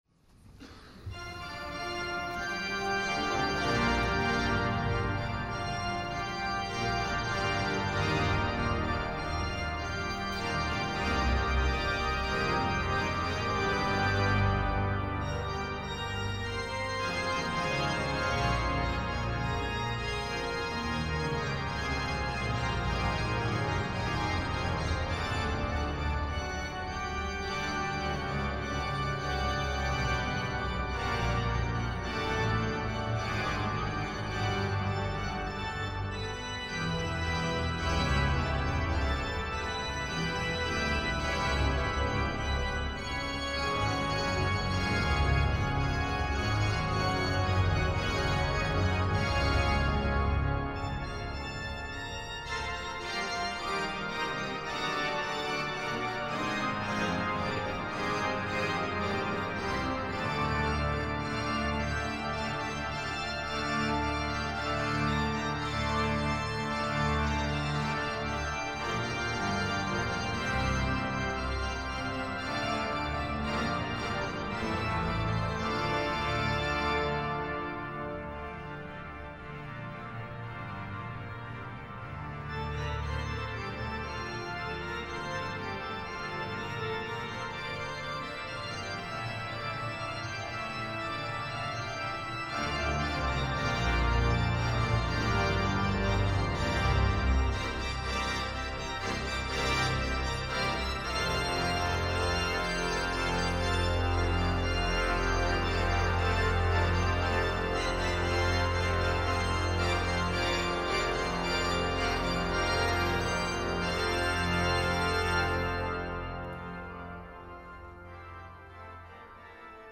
Kapitelsamt am einunddreißigsten Sonntag im Jahreskreis